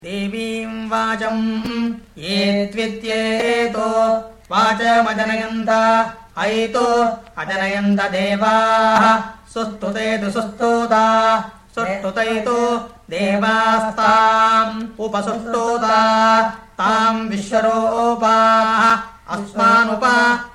Vikrti Recitation